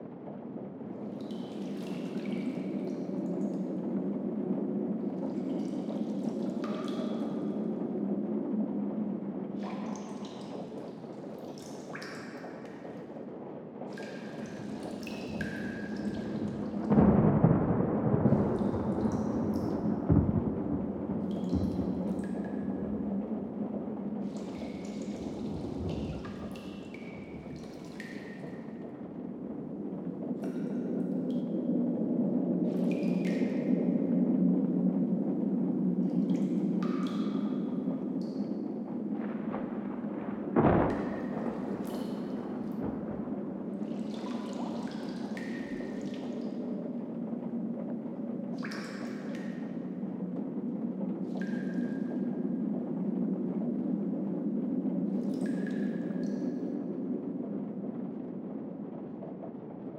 BGS Loops
Cave Storm.wav